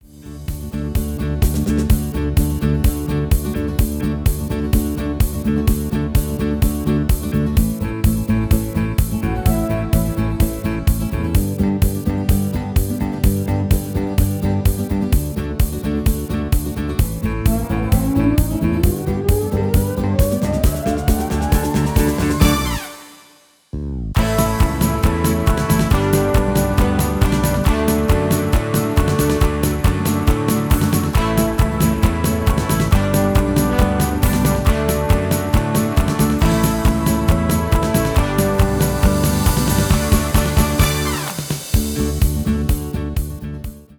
MP3-orkestband Euro 4.75